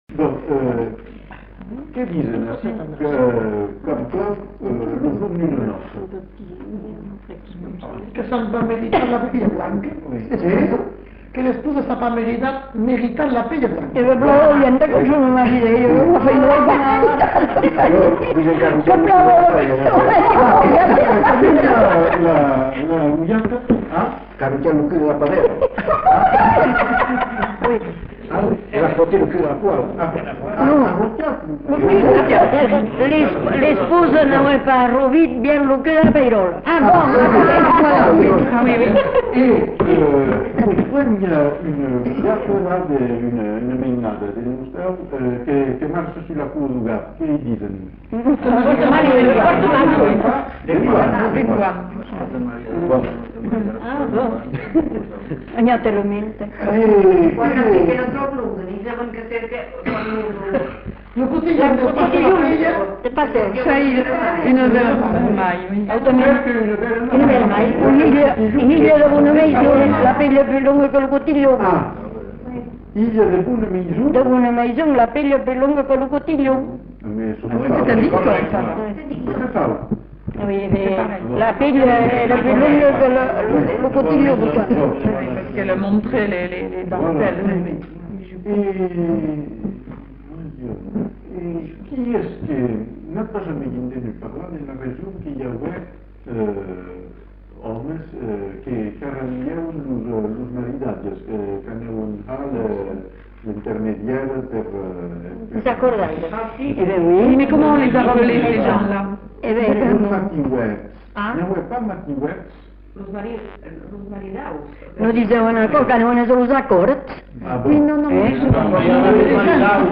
Description de la noce (avec bribes de chants)
Lieu : Villandraut
Genre : témoignage thématique